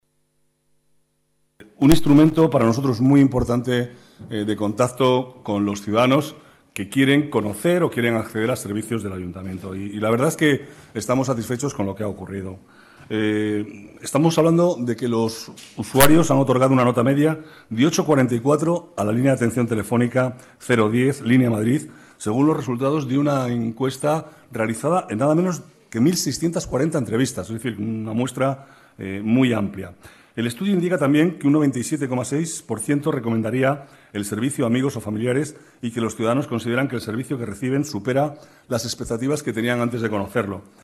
Nueva ventana:Declaraciones del vicealcalde, Manuel Cobo: Línea Madrid 010